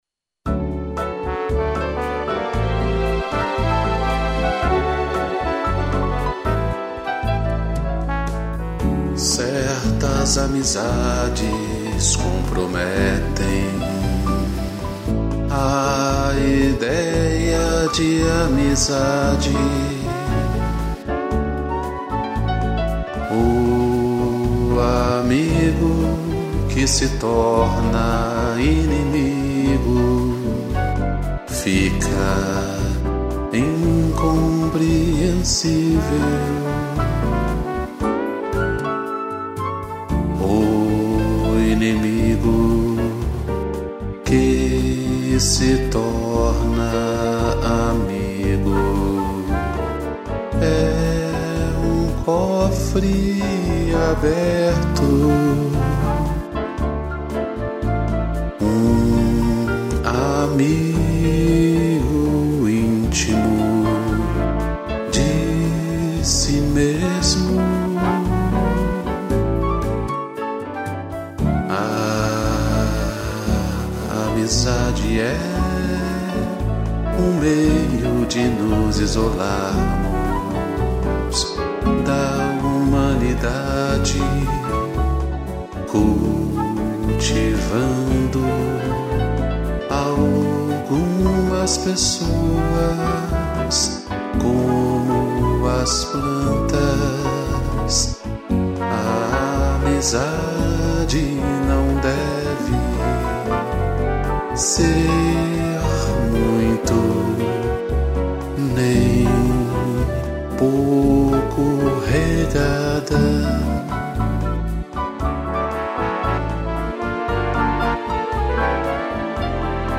Voz
piano, trombone e flauta